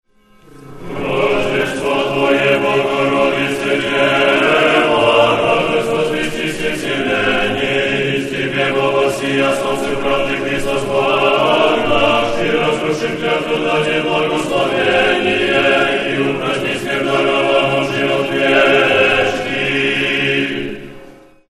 Тропарь Рождества Пресвятой Богородицы